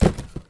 sfx_cardboard_lift.ogg